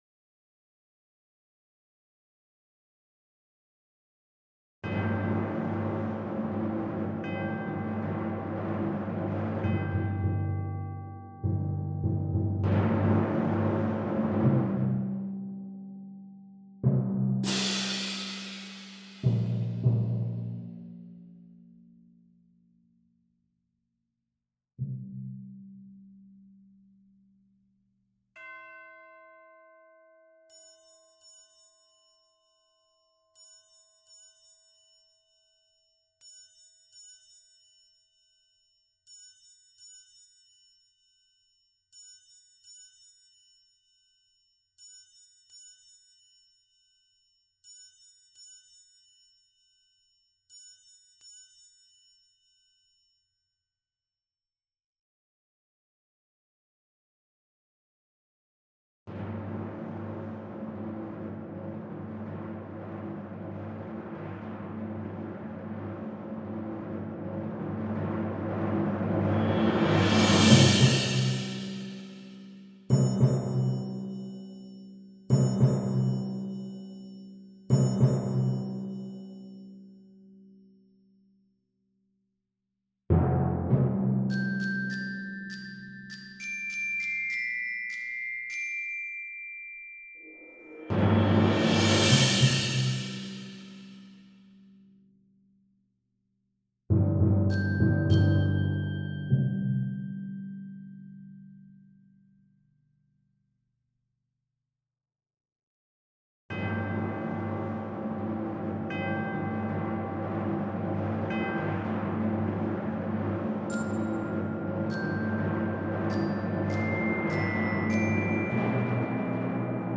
Christ Arose Perc Stem